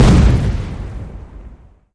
CannonFire.mp3